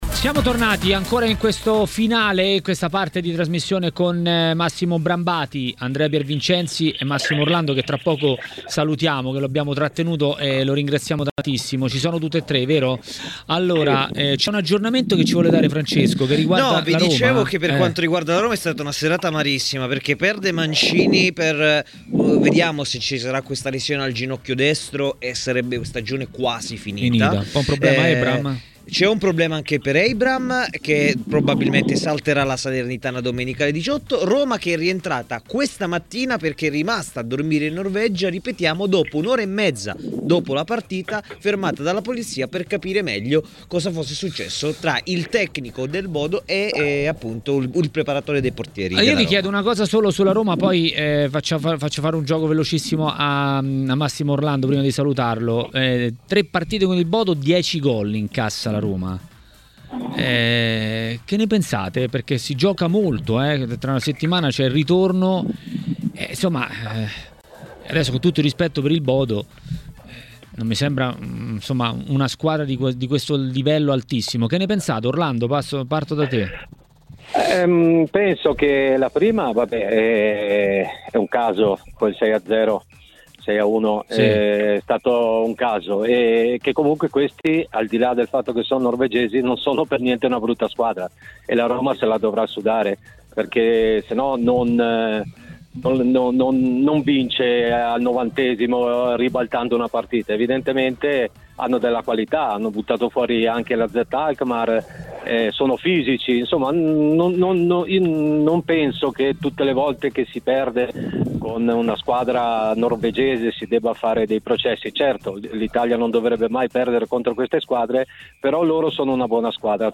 A TMW Radio, durante Maracanà, l'ex bomber Francesco Baiano ha commentato le notizie riguardanti la Serie A.